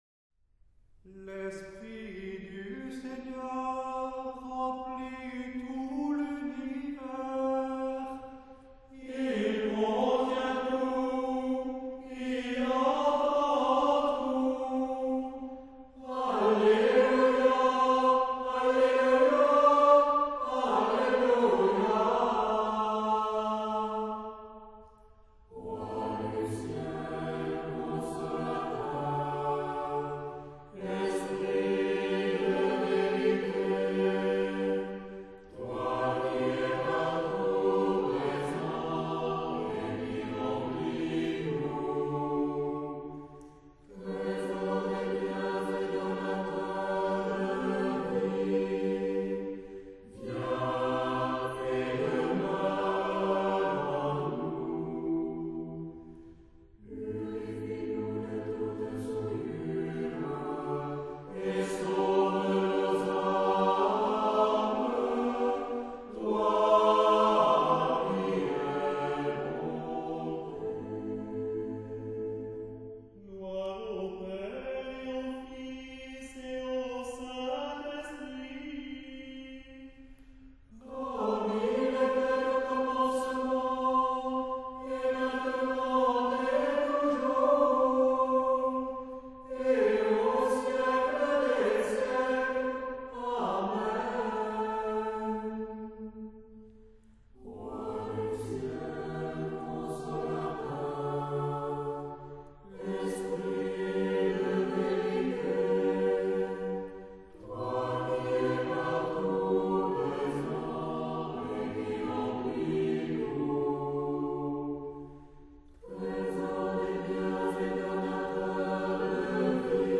Le Chant Liturgique
Le choeur orthodoxe est lui aussi construit sur ce modèle trinitaire : les deux, trois, quatre ou cinq voix chantent toutes la même louange sur des mélodies différentes mais qui sont harmonisées.